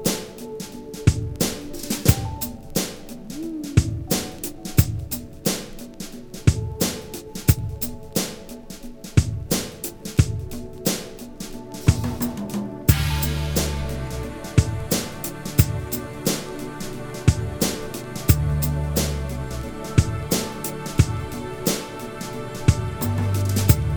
Pop (2000s)